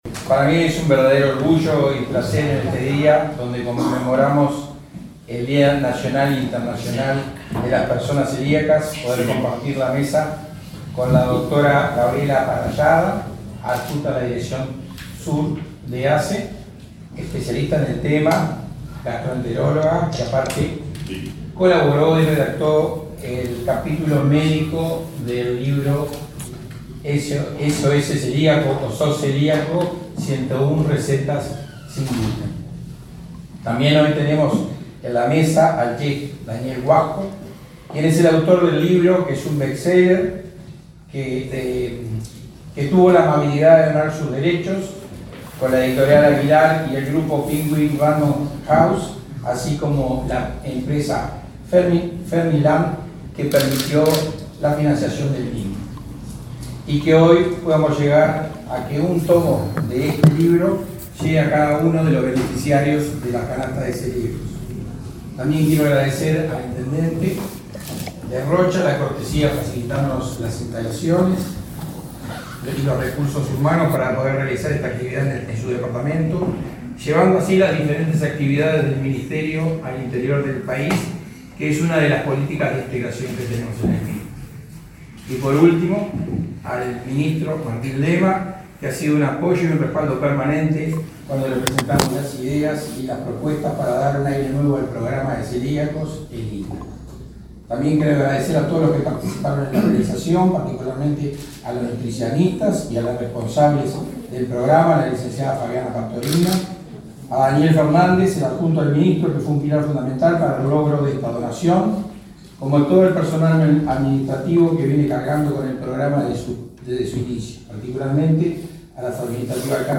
Palabras de autoridades en el Día Nacional del Celíaco
Palabras de autoridades en el Día Nacional del Celíaco 05/05/2022 Compartir Facebook X Copiar enlace WhatsApp LinkedIn En el marco del Día Nacional del Celíaco, el presidente del Instituto Nacional de Alimentación (INDA), Ignacio Elgue, y el ministro de Desarrollo Social, Martín Lema, destacaron, este jueves 5 en Rocha, el trabajo de esa cartera para atender a la población vulnerable que sufre esa patología.